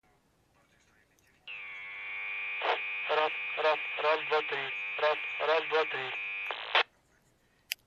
Шум при передаче